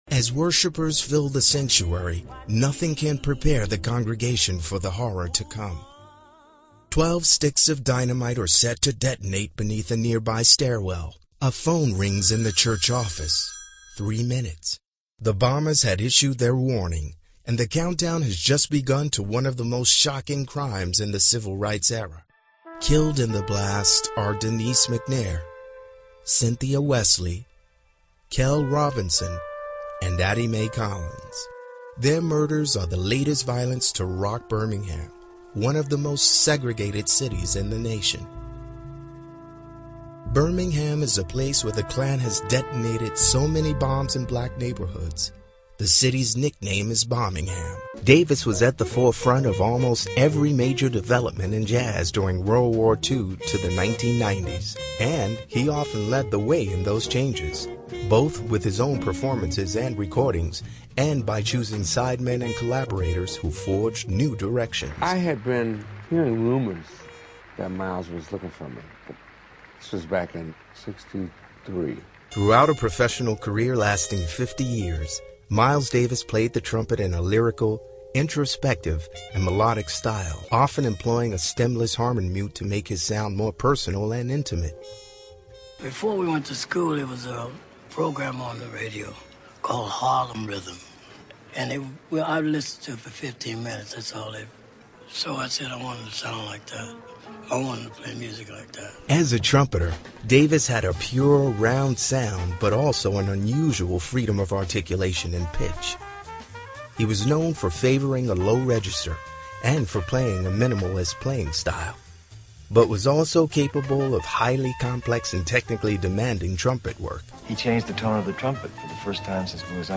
mid-atlantic
middle west
Sprechprobe: Sonstiges (Muttersprache):